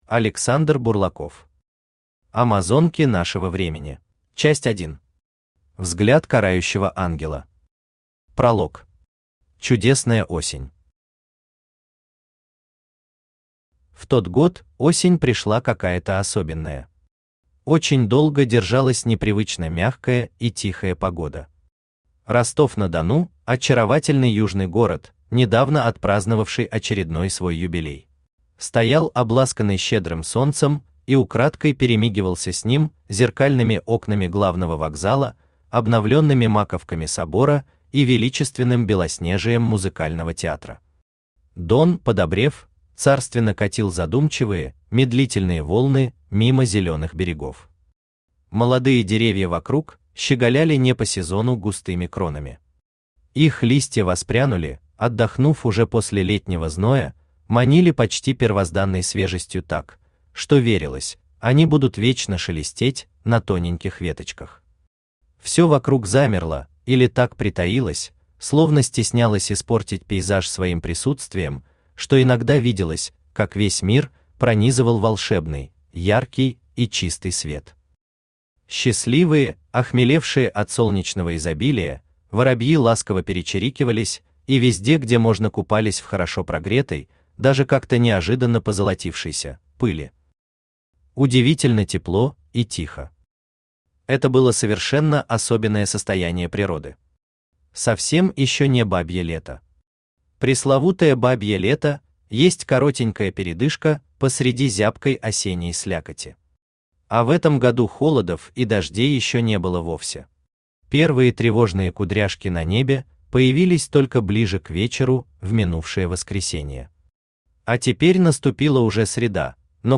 Аудиокнига Амазонки нашего Времени | Библиотека аудиокниг
Aудиокнига Амазонки нашего Времени Автор Александр Бурлаков Читает аудиокнигу Авточтец ЛитРес.